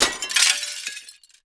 GLASS_Window_Break_07_mono.wav